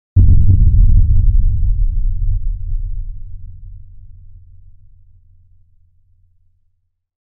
Deep Cinematic Hit Sound Effect
Description: Deep cinematic hit sound effect. Epic powerful deep hit sound effect adds intensity and impact to your project.
Scary sounds.
Deep-cinematic-hit-sound-effect.mp3